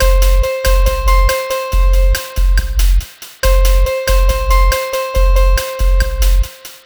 Cheese Lik 140-C.wav